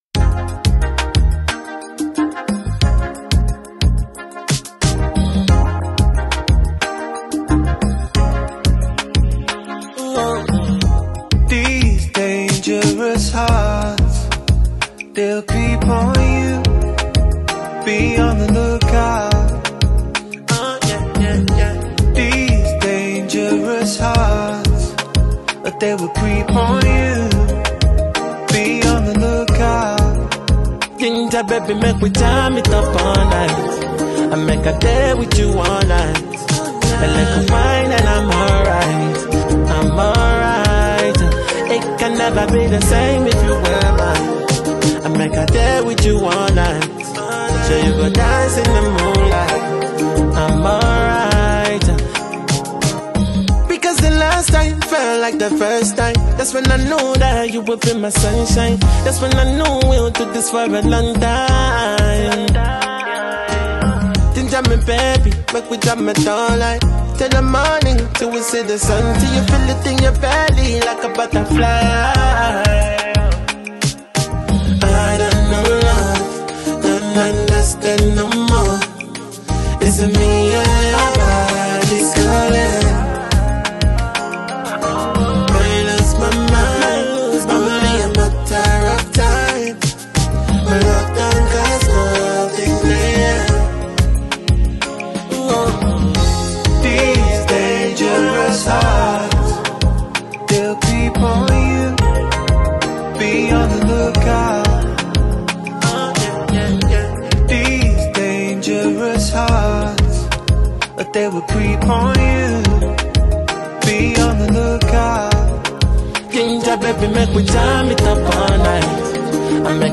a soulful fusion of Afrobeats and alt-pop.